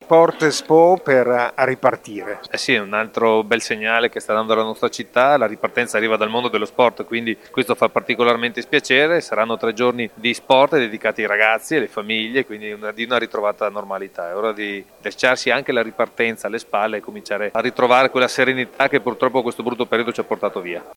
Filippo Rando assessore allo sport del Comune di Verona:
Filippo-Rando-assessore-allo-Sport-del-comune-di-Verona-su-Sport-Expo.mp3